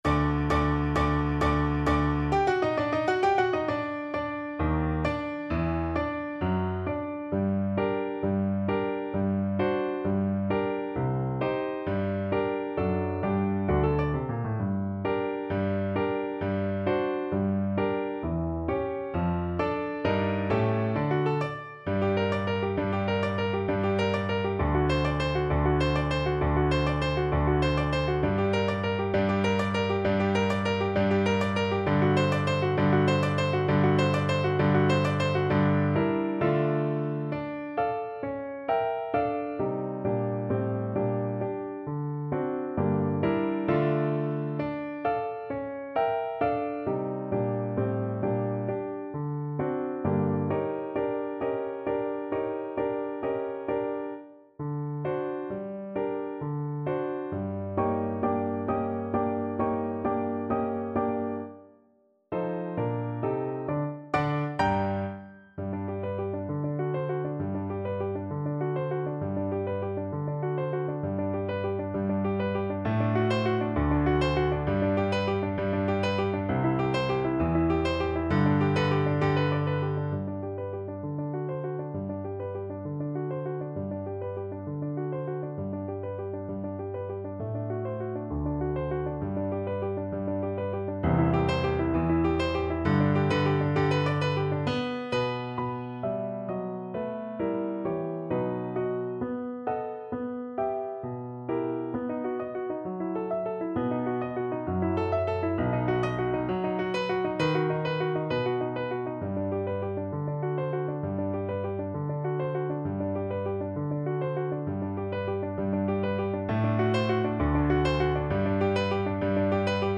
6/8 (View more 6/8 Music)
Classical (View more Classical Flute Music)